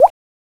DRIP.WAV